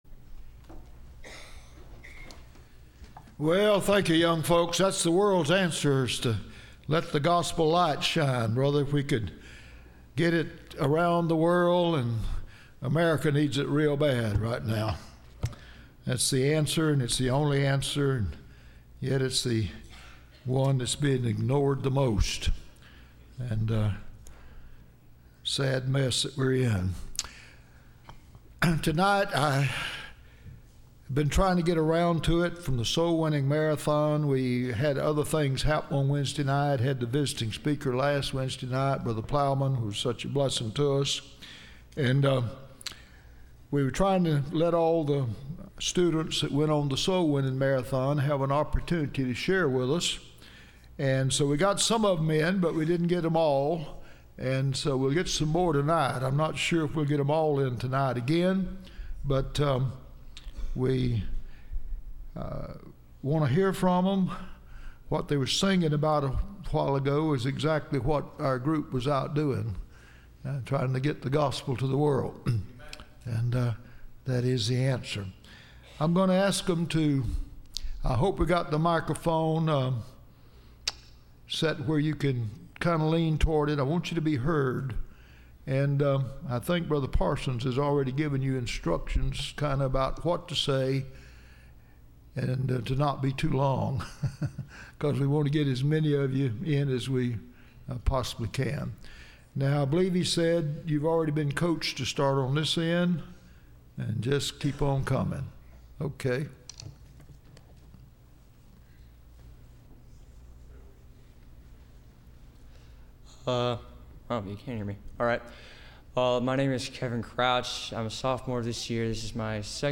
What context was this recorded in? Service Type: Wednesday College